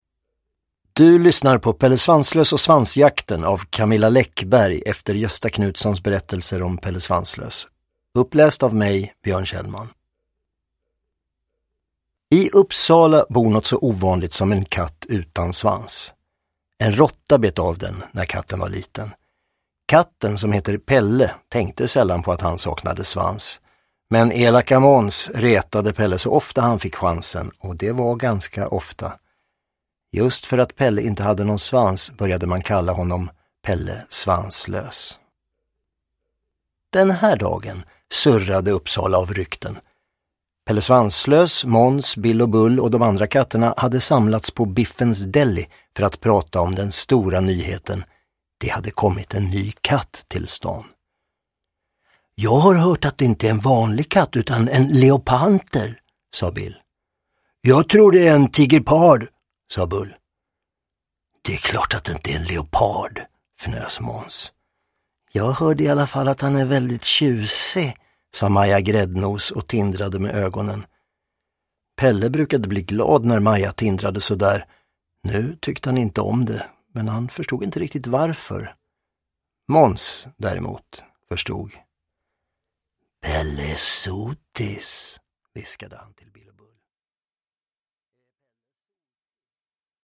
Pelle Svanslös och svansjakten – Ljudbok
Uppläsare: Björn Kjellman